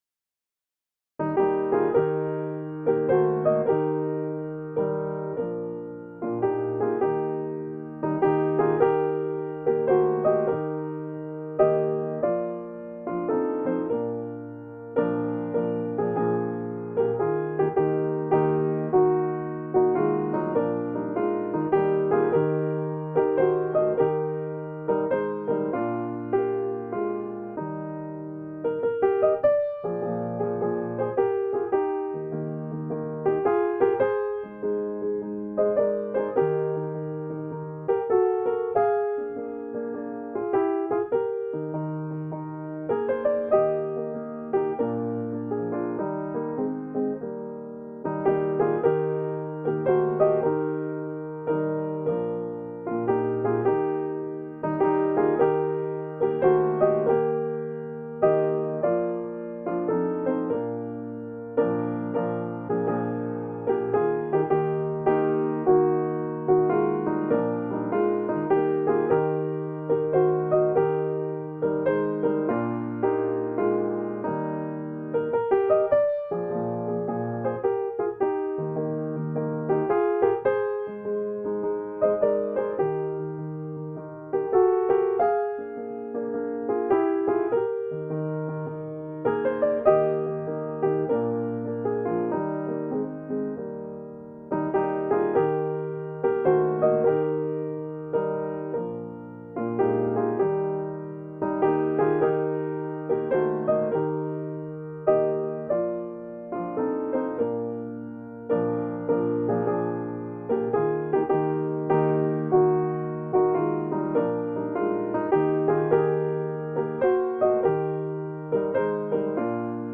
HYMN: Fanny Crosby